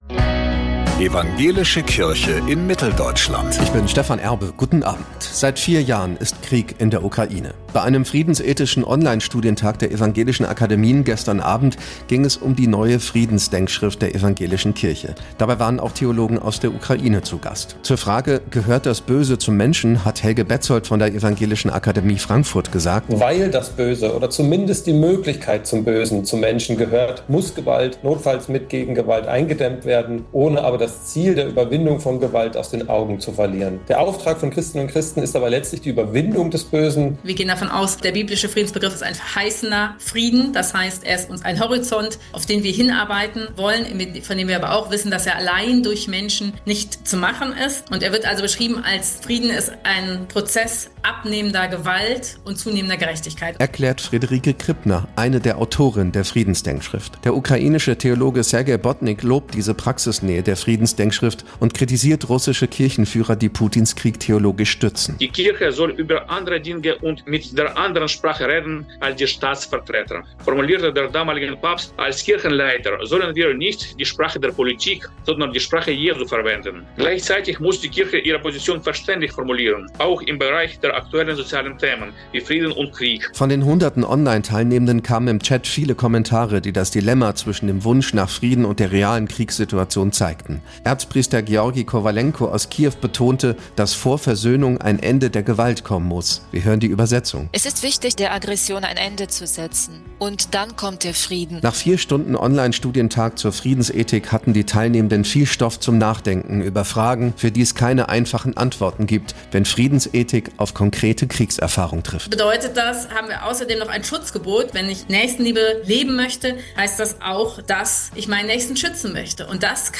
Neueste Radiobeiträge